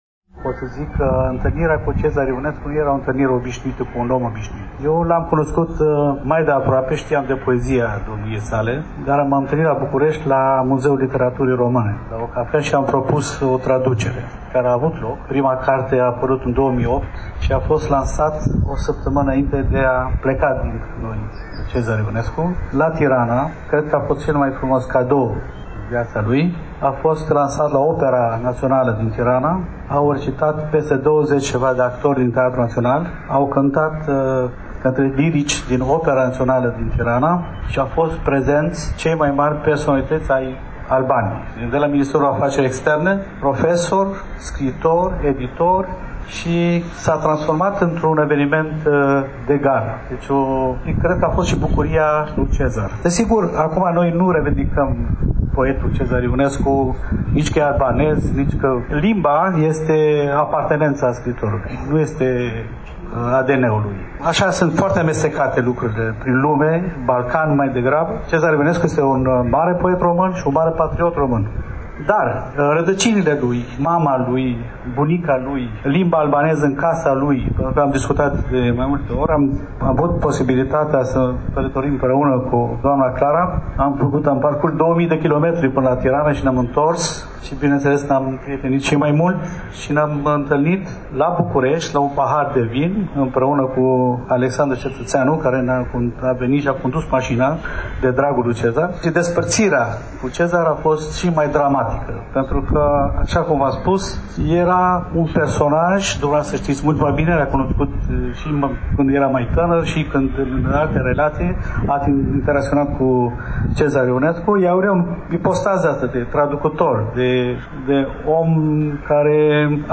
Concret, la 24 aprilie, începând cu ora 12 și 30 de minute, în fața sediului Editurii Junimea din Parcul Copou, s-a desfășurat un frumos eveniment, la care au participat o serie personalități de pe ogorul culturii naționale: scriitori, prieteni, membri ai familiei poetului Cezar Ivănescu, reprezentanți ai organizatorilor, oficialități.